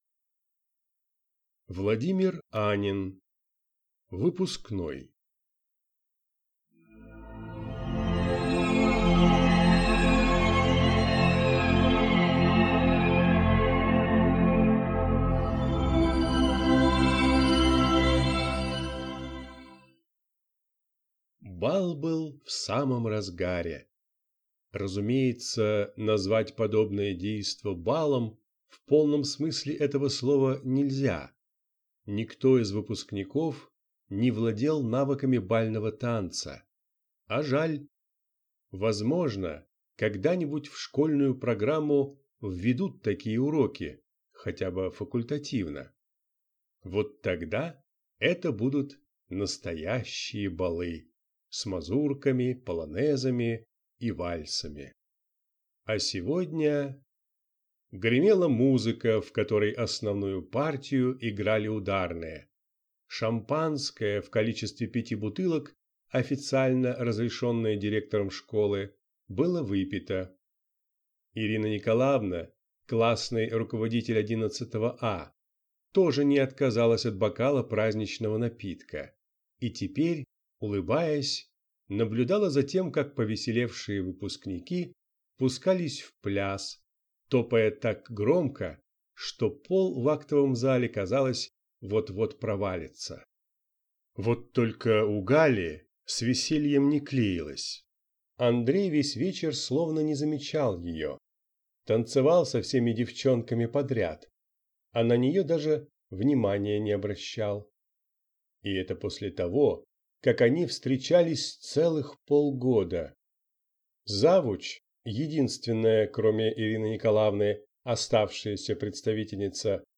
Аудиокнига Выпускной | Библиотека аудиокниг